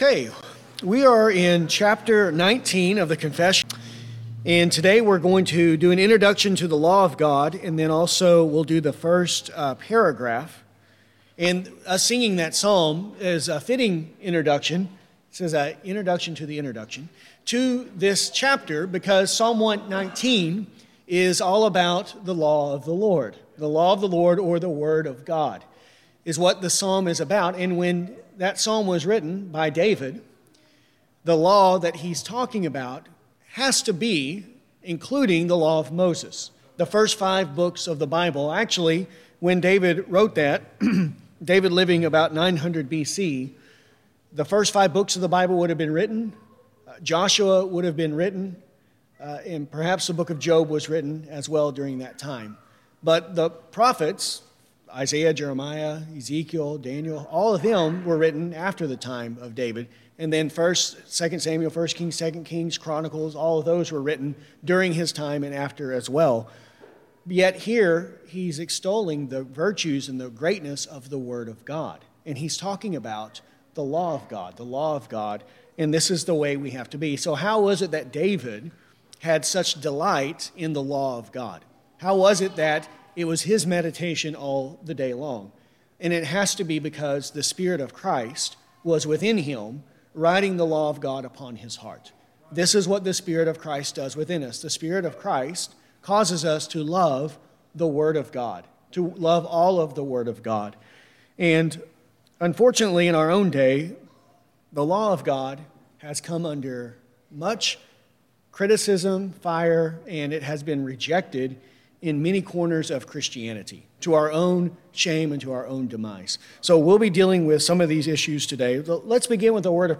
This lesson covers Paragraphs 19.1. To follow along while listening, use the link below to view a copy of the confession.